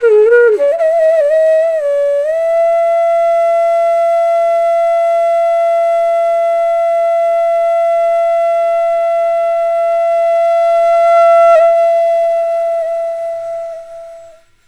FLUTE-A09 -L.wav